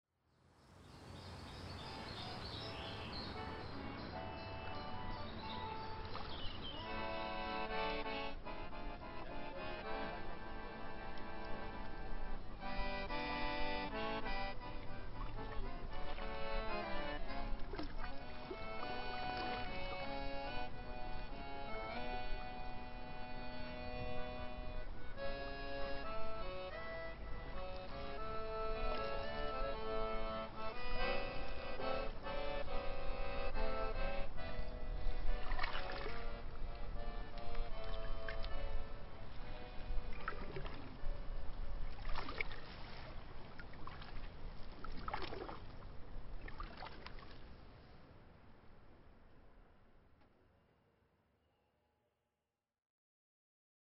The Pacific, the Hudson, the East River, her “vortex”, her “embryonic journey”, and what is represented in this next cue, a day walking around Paris topped off by a dip in a rooftop pool.